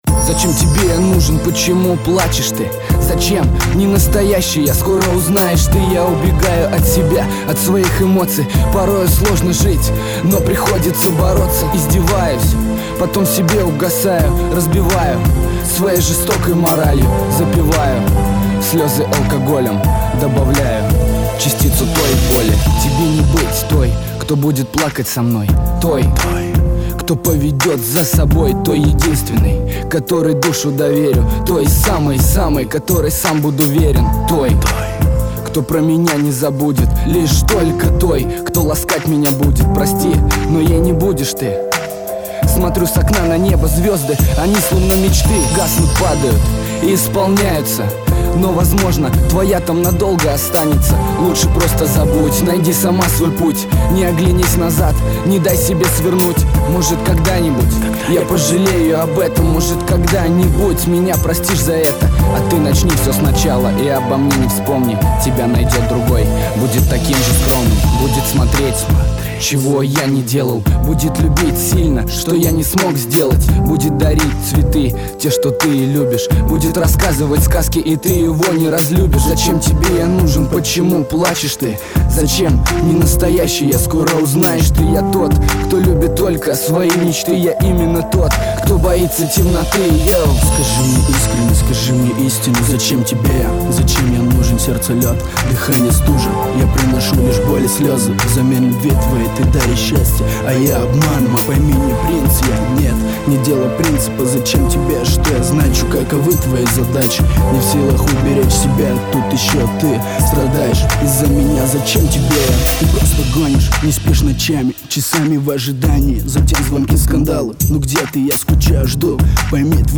krasivyj_rep_zachem_tebe_ya_nuzhen_mp3IQ_.mp3